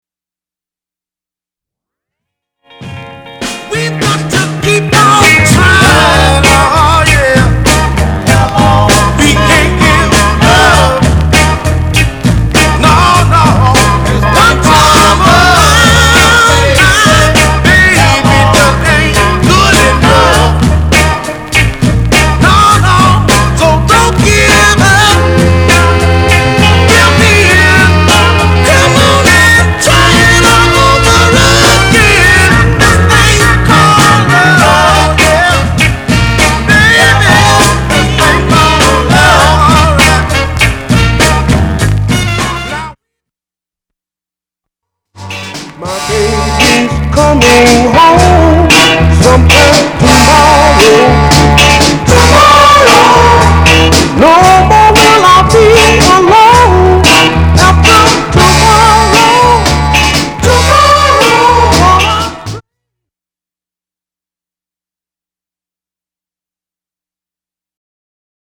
当前位置 > 首页 >音乐 >唱片 >R＆B，灵魂
/盤質/両面目立った傷無し/US PRESS